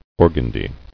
[or·gan·dy]